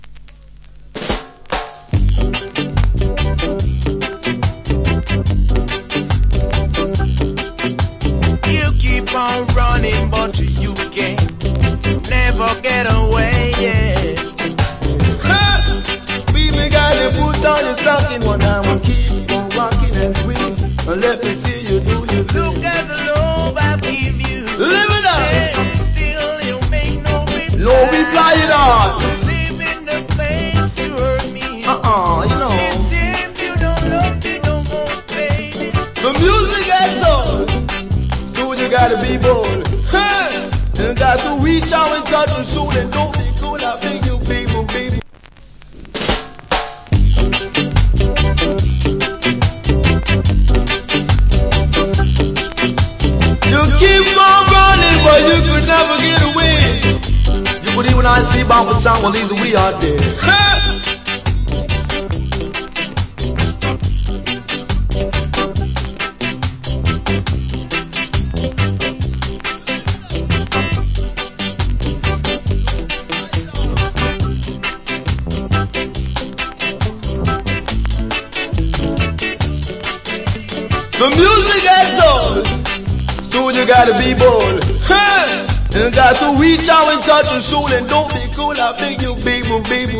Wicked DJ + Vocal.